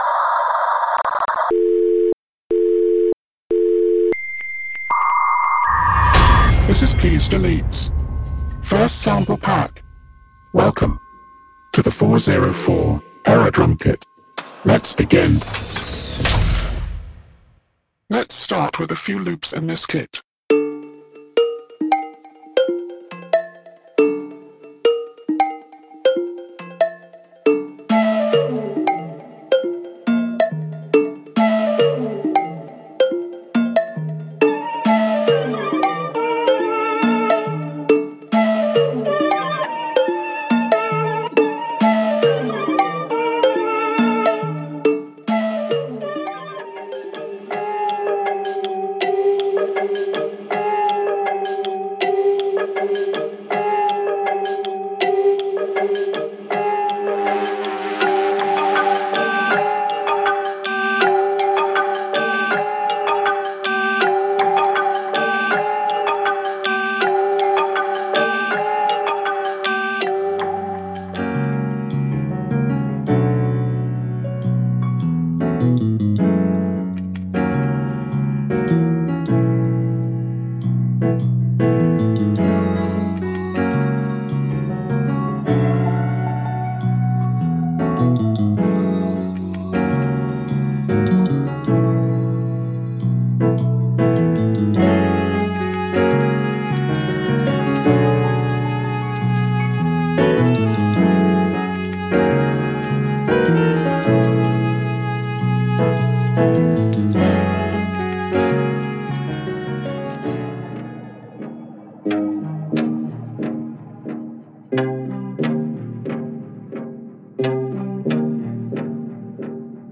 All drums or loops have been processed with:
“WA76 FET Compressor”
• 32 Live Drums